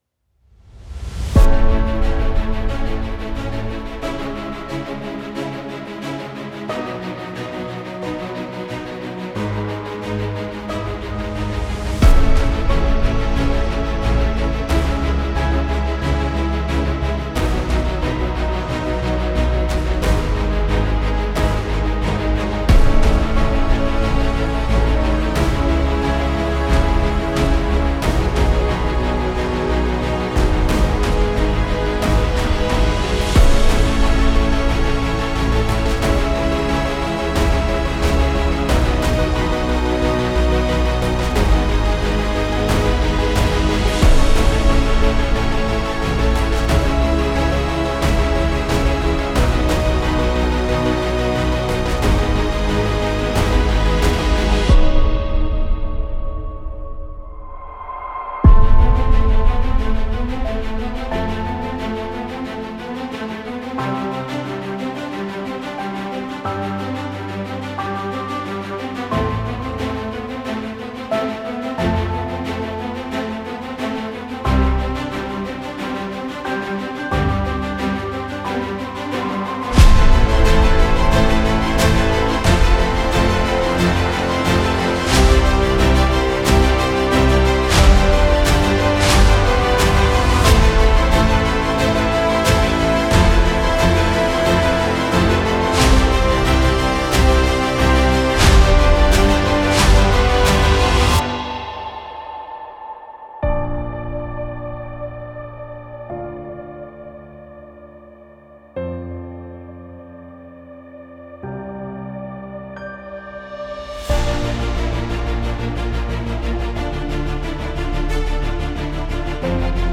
ブーム、ヒット、ピング、トランジションなどが含まれています。
Genre:Filmscore
062 Drums
055 Orchestral
026 Pianos
020 Synths